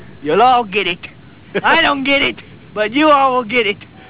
Brak Radio Interview